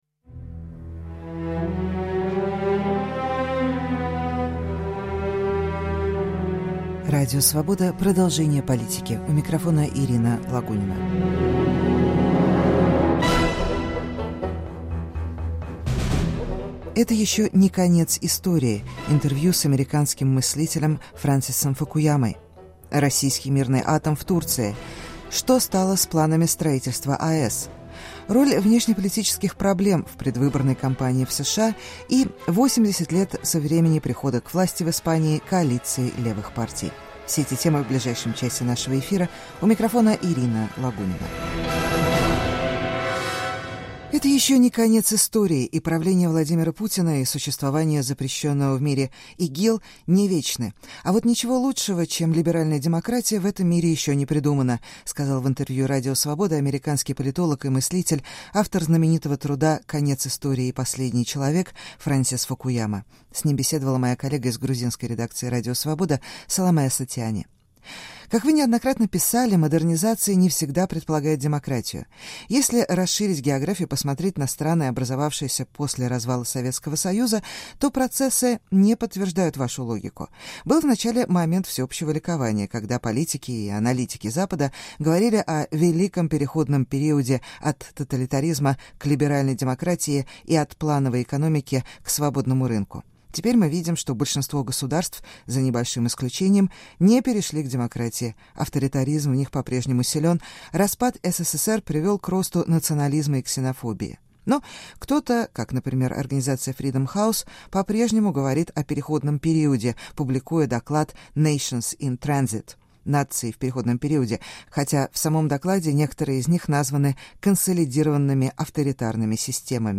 Интервью с американским мыслителем Фрэнсисом Фукуямой.